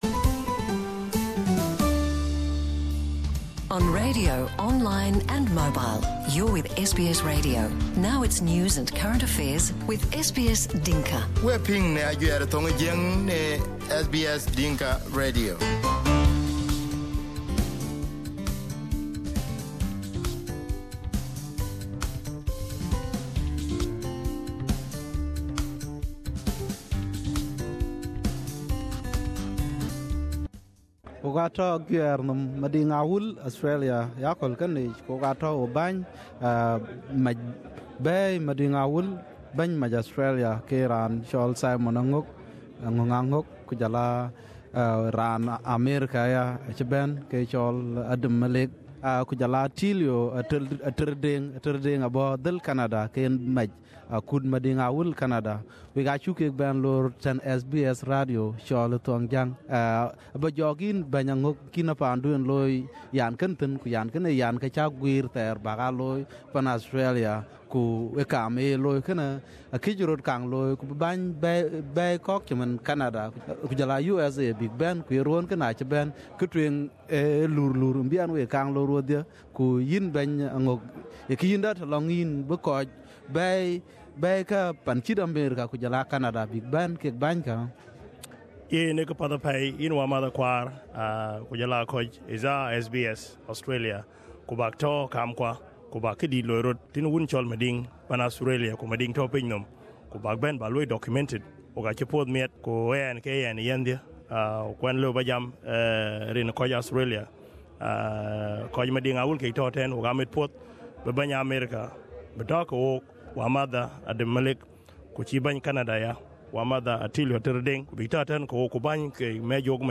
The leaders in this interview spoke about the importance of working together at various levels to achieve intended outcomes.